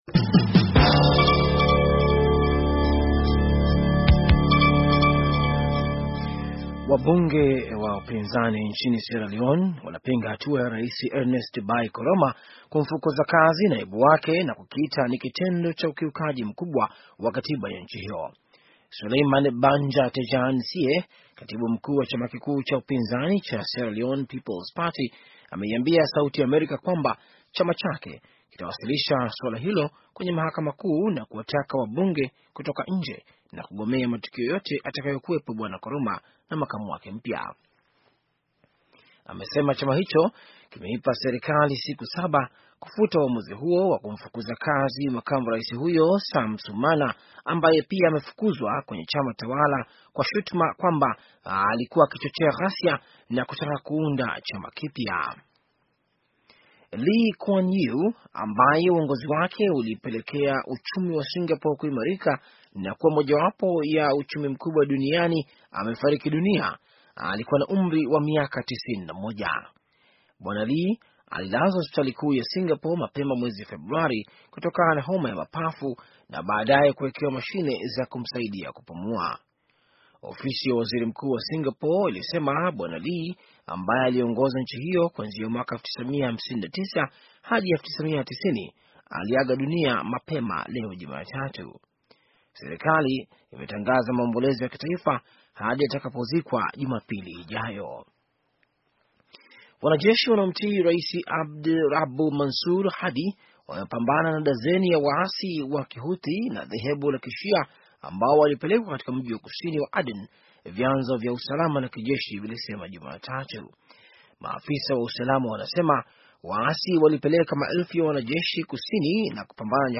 Taarifa ya habari - 5:19